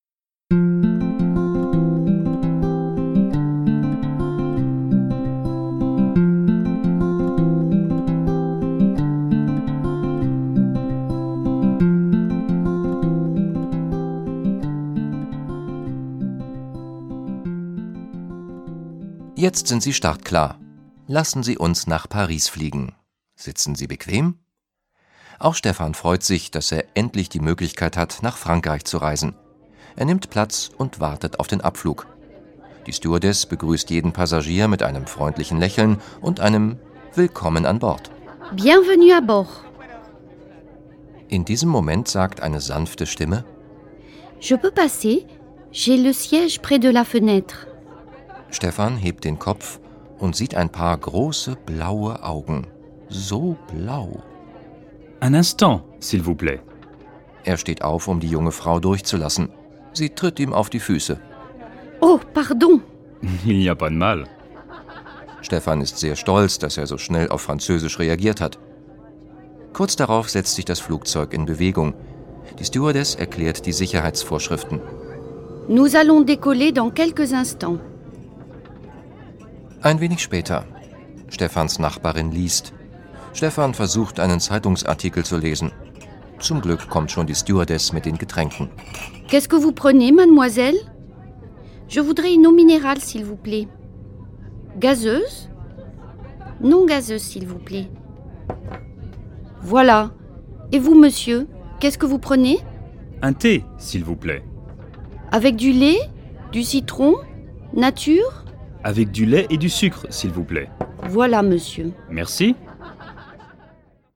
Der Sprachkurs zum Hören mit 4 Audio-CDs und Begleitbuch
• Vier Stunden Hörvergnügen - professionell vertont von Muttersprachlern